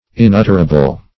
Inutterable \In*ut"ter*a*ble\, a.
inutterable.mp3